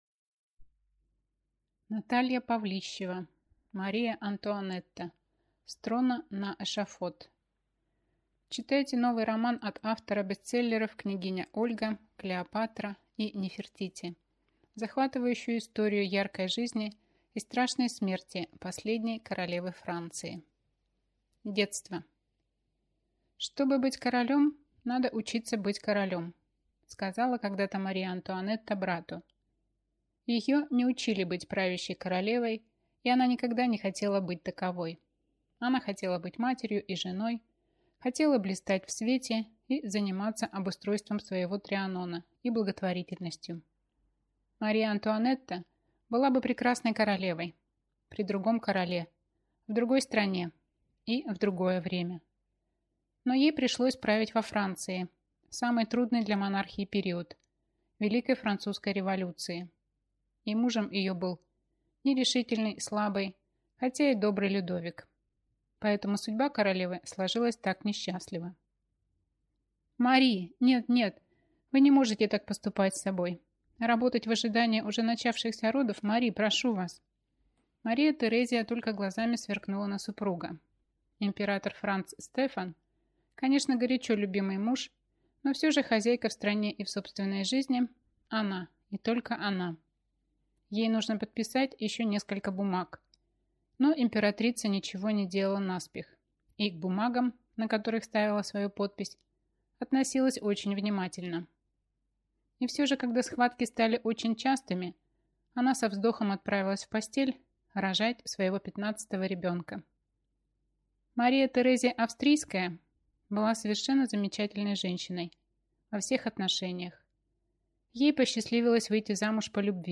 Аудиокнига Мария-Антуанетта. С трона на эшафот | Библиотека аудиокниг